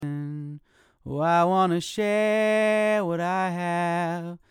Weird Sound in a Recording
In this example I was recording from an MXL-990 -> USB Interface (Edirol UA-25) -> Reaper. You're hearing a dry signal.
View attachment share_weirdness.mp3 So where the heck is that flanging like sound coming from around the lyric "share"?